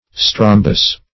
Strombus \Strom"bus\, n. [L., fr. Gr. ?.] (Zool.)